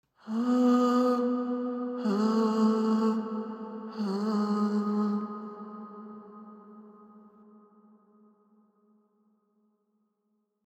REVERB:
fl-studio-vocals-reverb.mp3